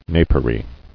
[na·per·y]